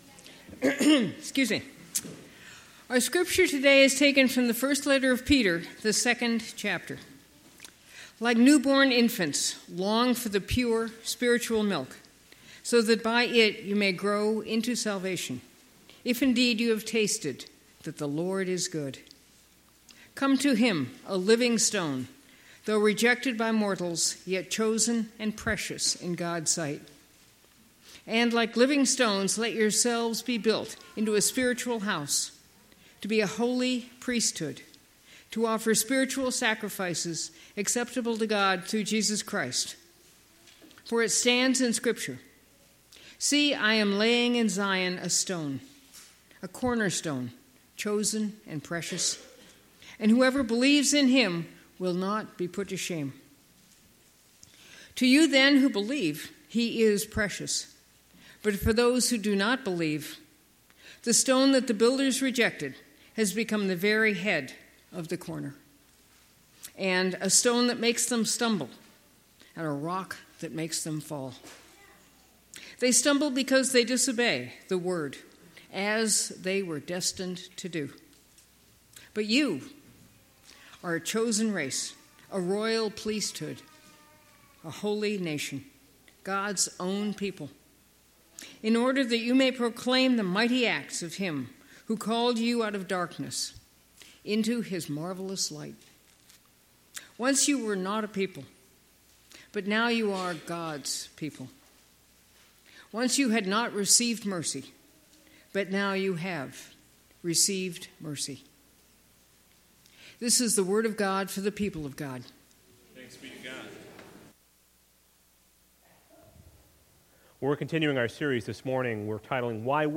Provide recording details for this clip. Why Worship Passage: 1 Peter 2:2-10 Service Type: Sunday Morning Topics